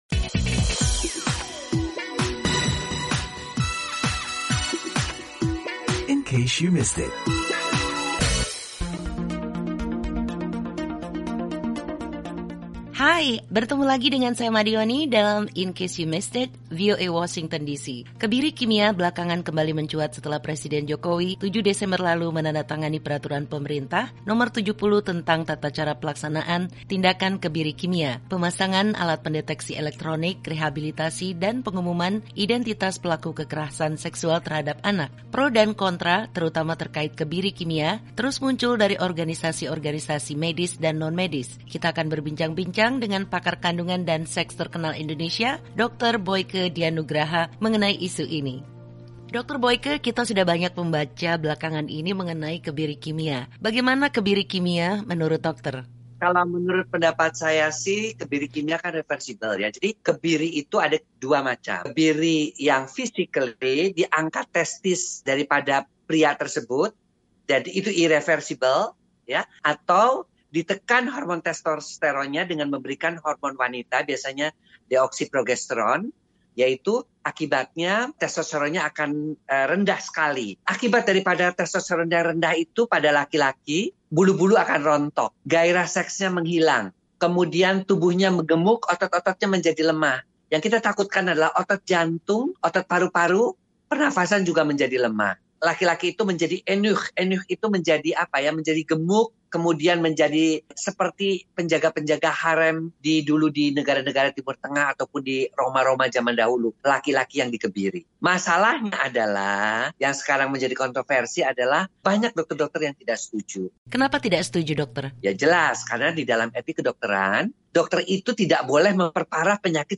berbincang dengan pakar kandungan dan pendidikan seks, Dr. Boyke Dian Nugraha, tentang dampak kesehatan kebiri kimia bagi pelaku kekerasan seksual. Desember lalu, Presiden Jokowi menandatangani Peraturan Pemerintah No. 70, yang salah satunya menjabarkan tata cara kebiri kimia.